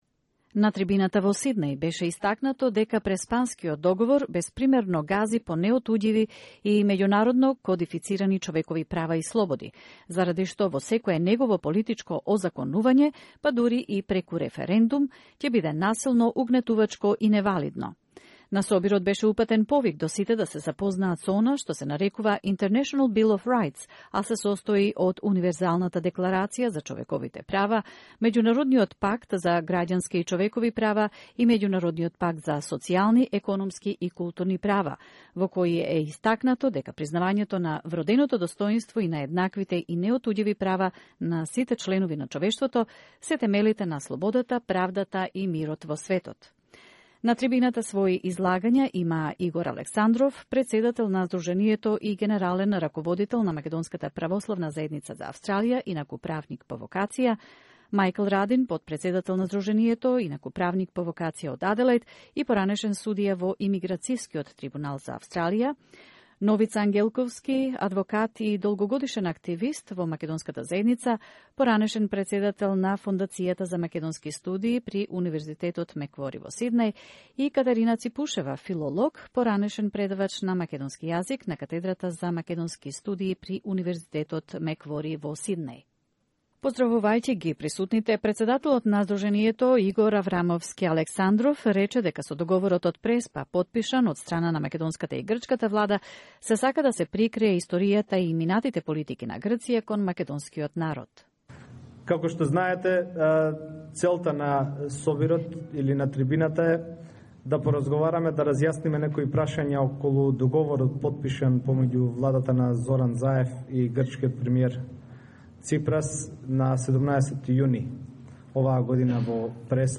The content of the Prespa Agreement is not openly discussed by politicians and the media when they speak only about the change of the name of the country, was said at a forum organized by the Association of Macedonian Communities in Australia that took place yesterday (July 5th) in the Macedonian Sports Center Ilinden in Sydney.